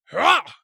人声采集素材/男3战士型/ZS发力6.wav